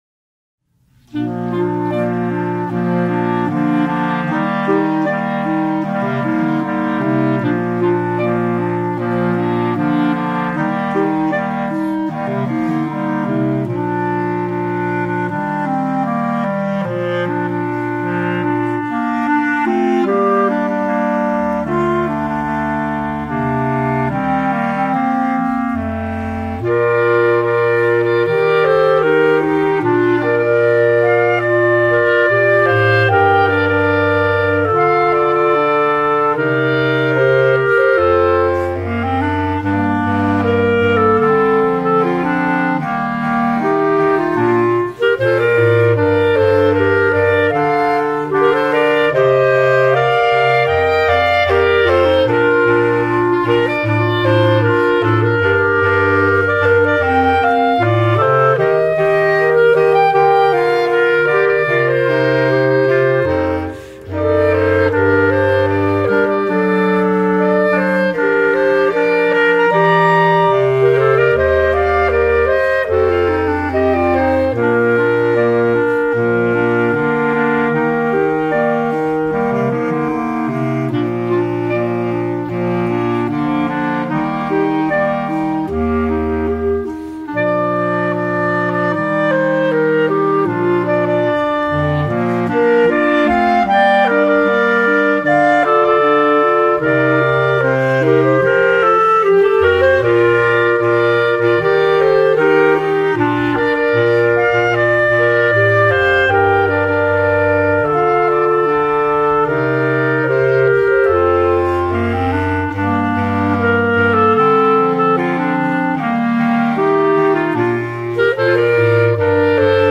B♭ Clarinet 1 B♭ Clarinet 2 B♭ Clarinet 3 Bass Clarinet
单簧管四重奏
流行
与优美动人的主旋律相呼应，伴奏部分被编排得既帅气又华丽。